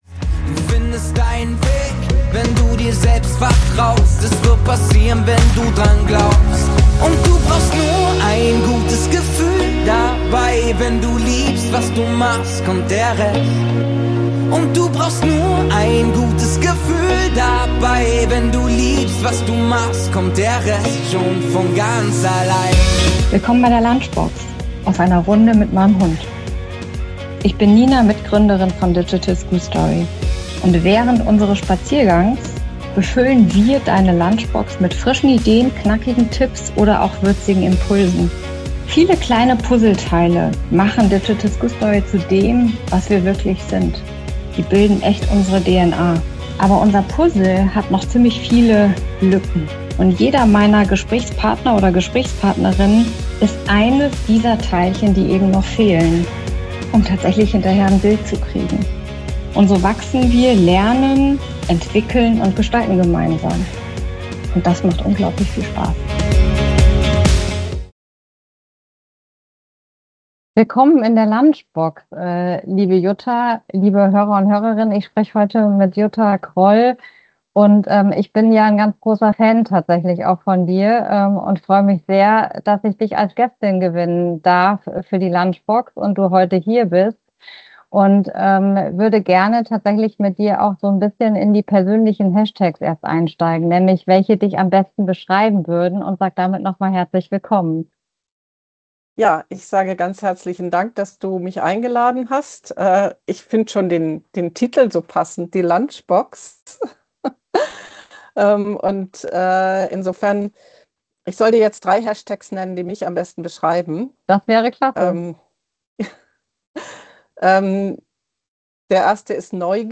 Ein Gespräch über Neugier, Verantwortung und die Frage, wie wir Kindern wirklich auf Augenhöhe begegnen.